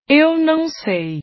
Voz feminina do tradutor do Google diz "eu não sei".
voz-do-google-eu-nao-sei.mp3